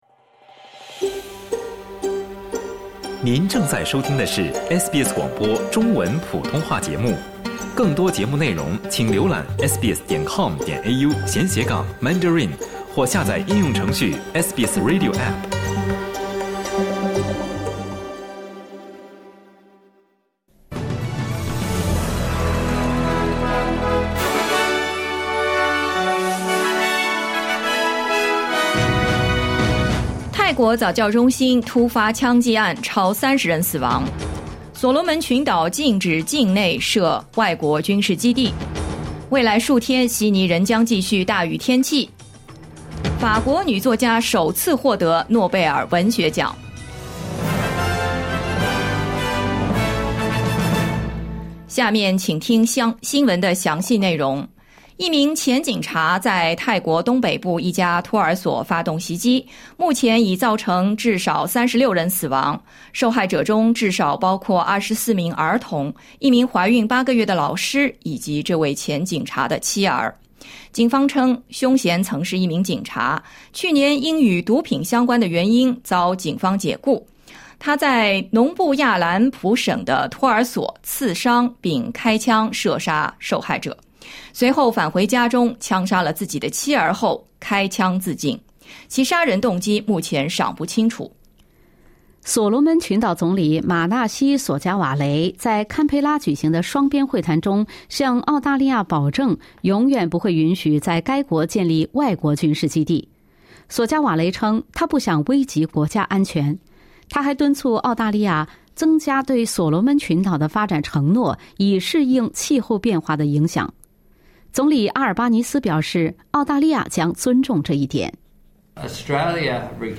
欢迎点击收听SBS普通话为您带来的最新新闻内容。